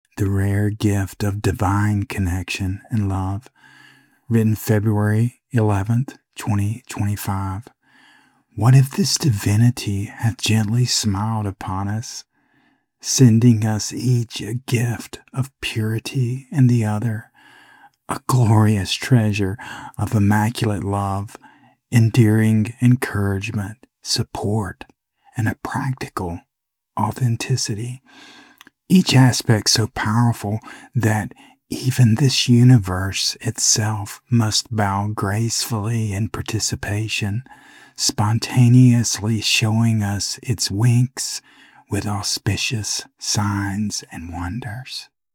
Spoken Audio